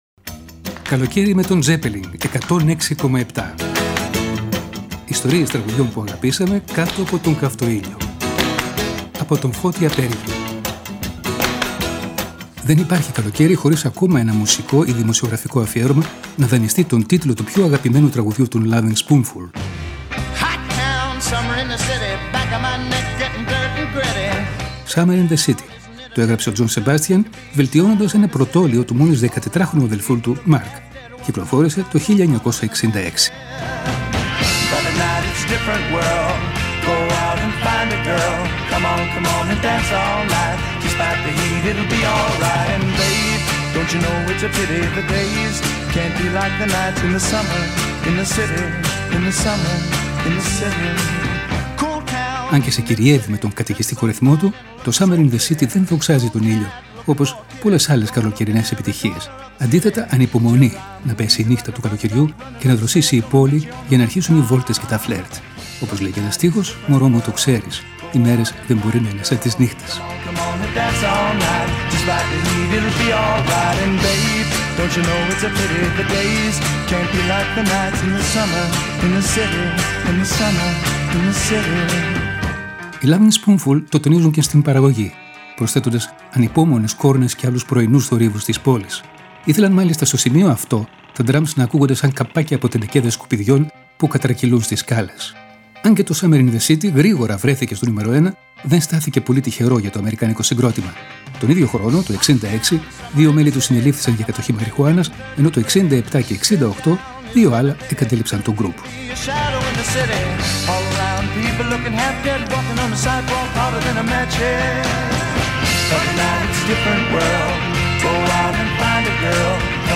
Οι Lovin Spoonful το τονίζουν και στην παραγωγή, προσθέτοντας ανυπόμονες κόρνες και άλλους πρωινούς θορύβους της πόλης. Ηθελαν μάλιστα στο σημείο αυτό τα ντραμς να ακούγονται σαν καπάκια από τενεκέδες σκουπιδιών, που κατατρακυλούν στις σκάλες.